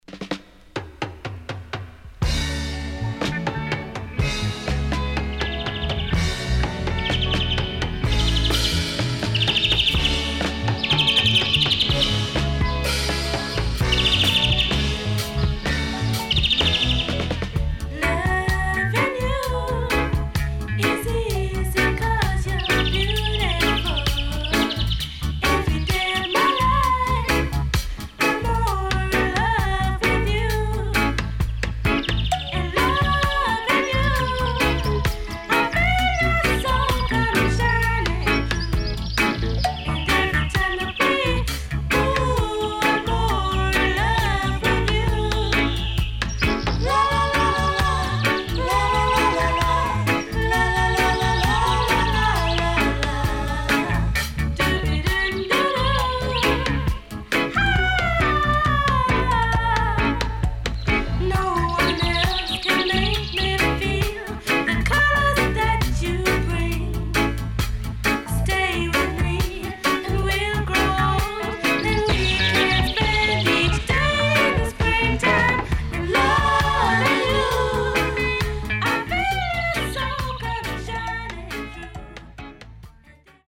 後半Dubwise接続.鳥のさえずりが強調されたバードホイッスルテイク
SIDE A:少しチリノイズ入りますが良好です。